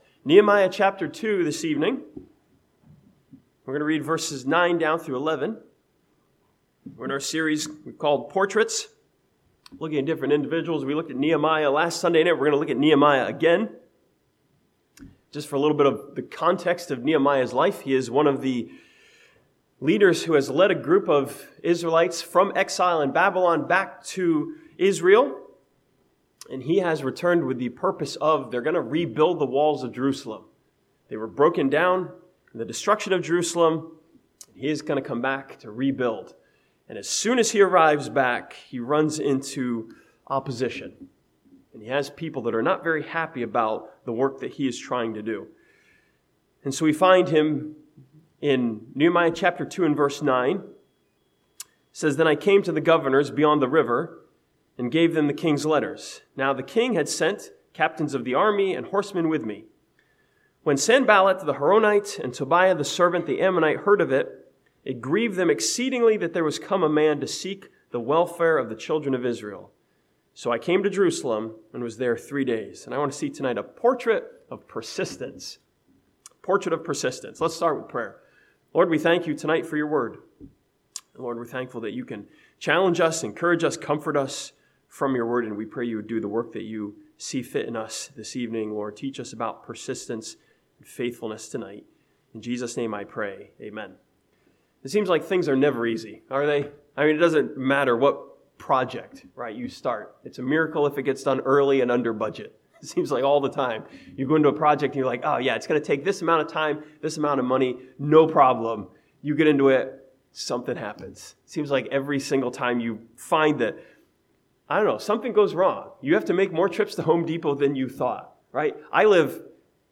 This sermon from Nehemiah chapter 2 challenges us with Nehemiah's persistence in the face of opposition.